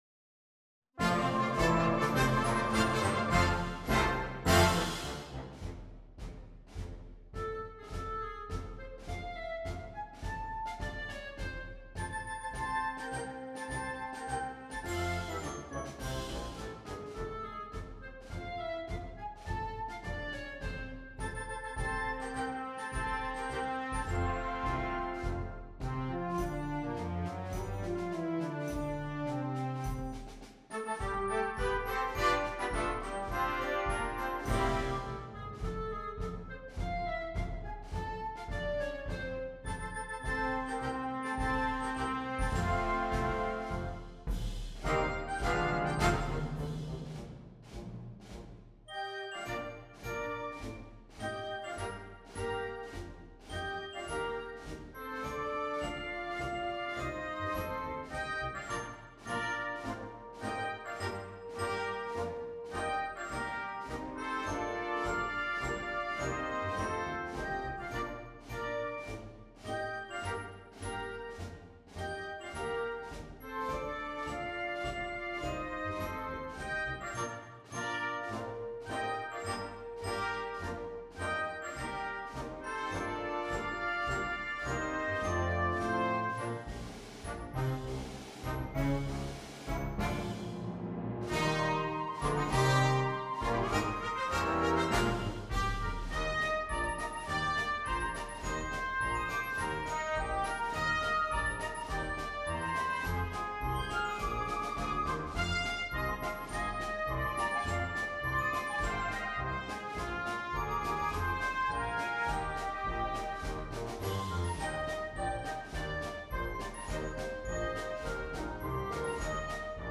Föreningens marsch